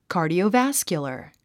発音 kɑ̀ːrdiouvǽskjulər カァディオバスキュラァ
cardiovascular.mp3